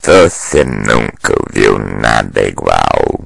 标签： 呼声 语音 坟墓 收音机 葡萄牙语 stonger 葡萄牙
声道立体声